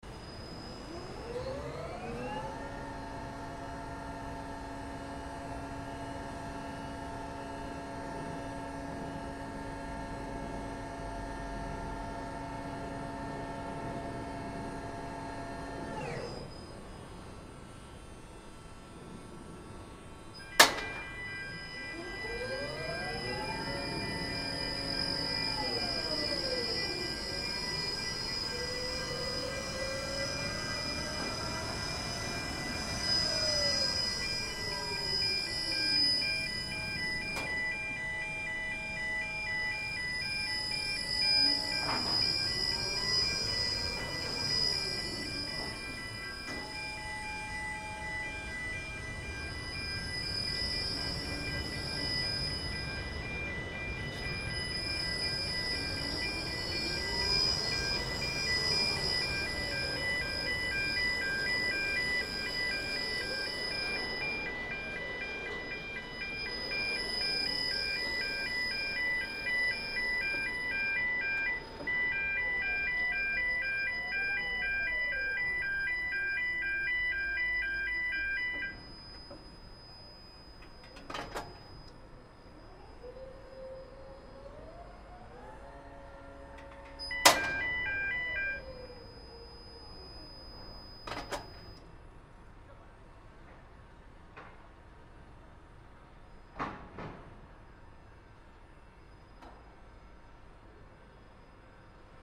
На этой странице представлены звуки башенного крана — мощные и ритмичные шумы строительной техники.
Движение крана вперед и назад